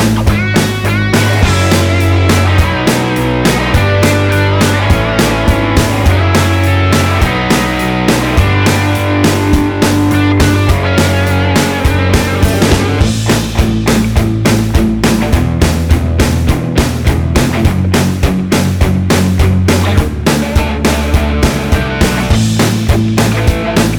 Live Version Pop (1970s) 3:35 Buy £1.50